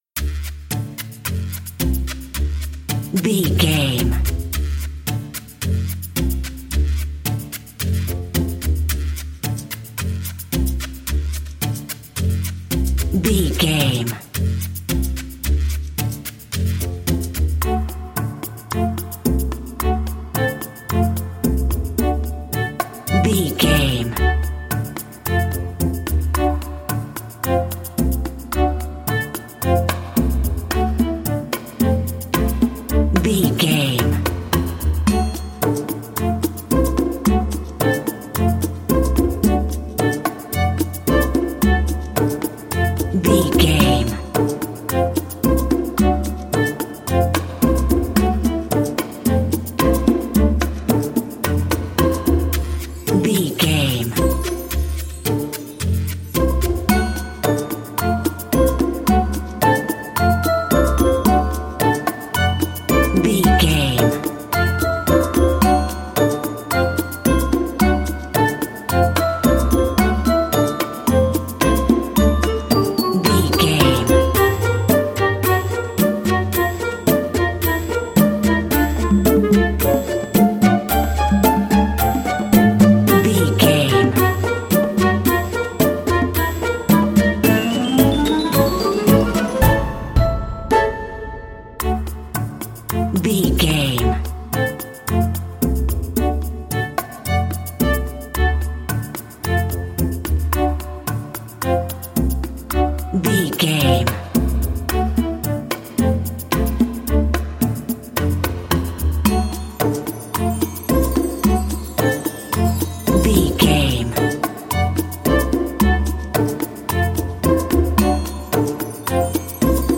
Ionian/Major
D
orchestra
flutes
percussion
conga
oboe
strings